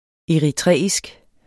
Udtale [ eɐ̯iˈtʁεˀisg ]